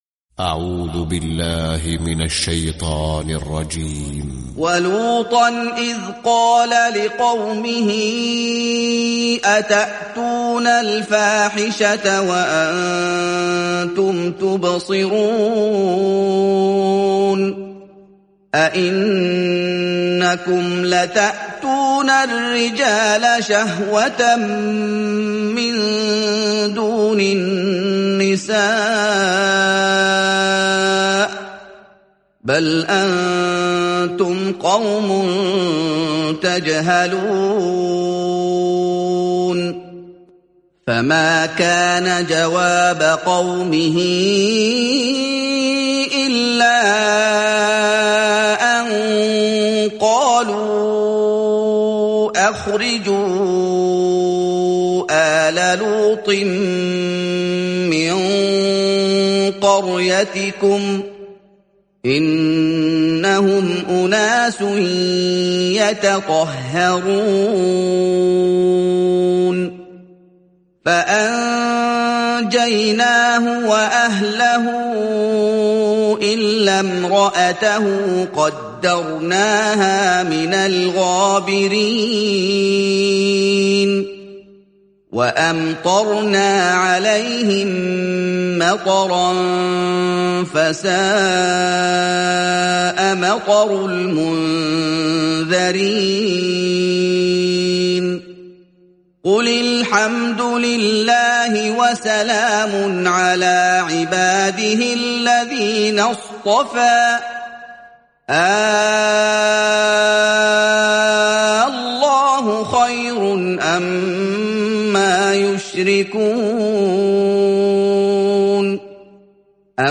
🌾💛•تلاوة مميزة•💛🌾
👤القارئ : محمد أيوب